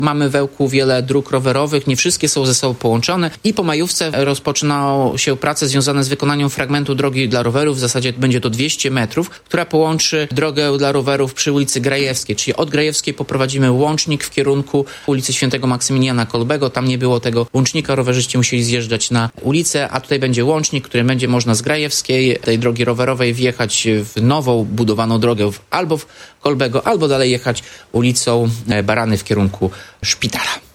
Jak mówi Tomasz Andrukiewicz, prezydent Ełku, powstanie dedykowany im łącznik między ulicami: Grajewską i św. Maksymiliana Marii – Kolbego.